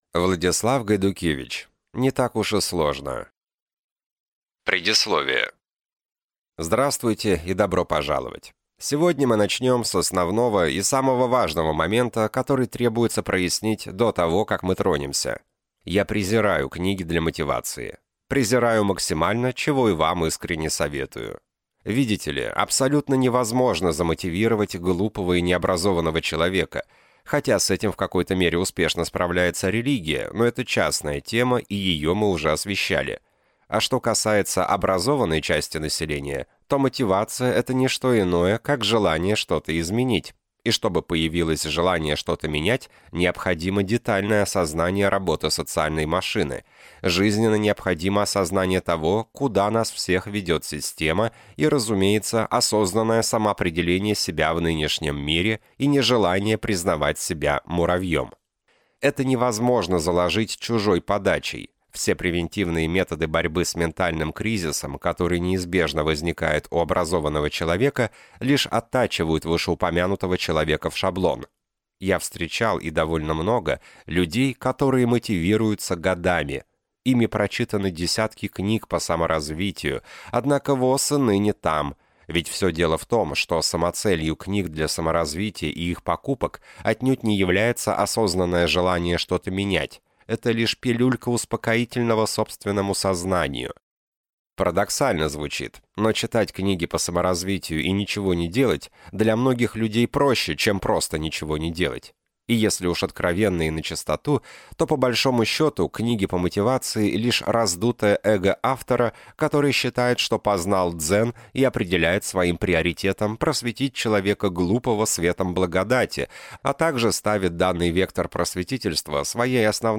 Аудиокнига Не так уж и сложно | Библиотека аудиокниг